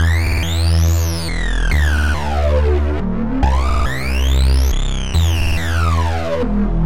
Tag: 14 bpm Dubstep Loops Synth Loops 1.16 MB wav Key : Unknown